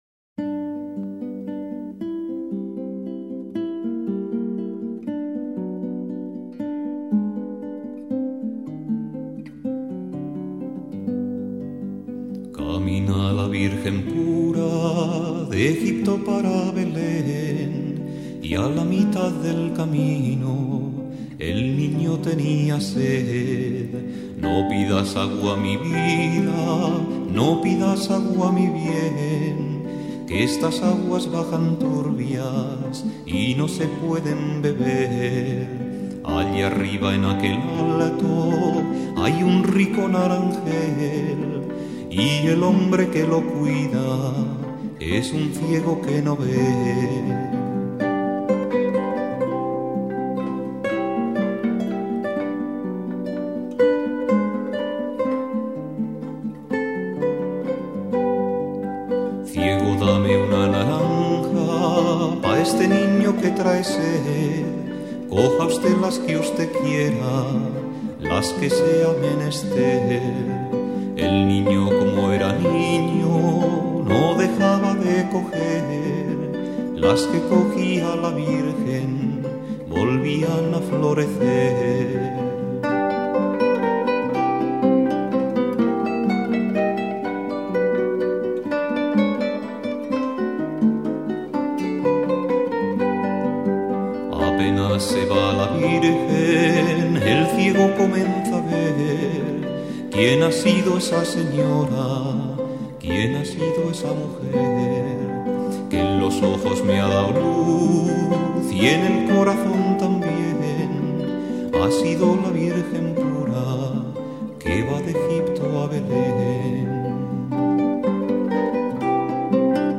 Guitarra y voz
Guitarra